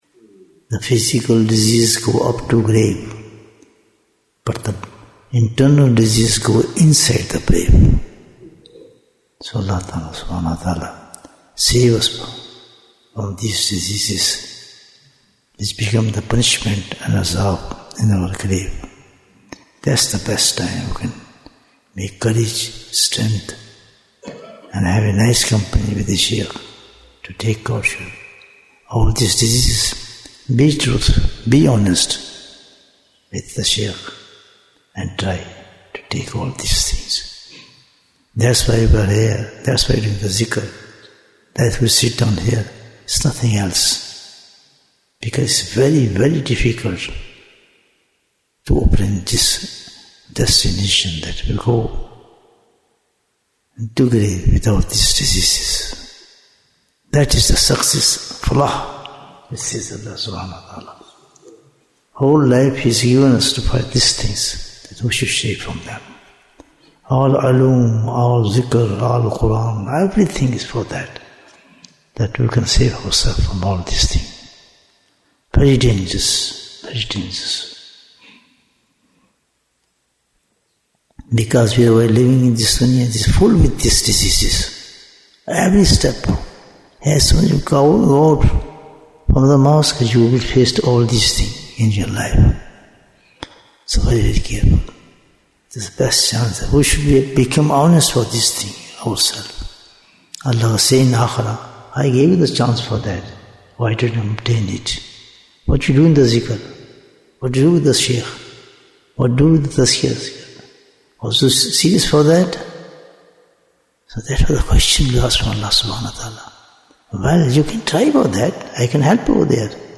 - Part 19 Bayan, 38 minutes 16th February, 2026 Click for Urdu Download Audio Comments Why is Tazkiyyah Important? - Part 19 Dhikr is the medicine which removes the spiritual diseases from one's heart.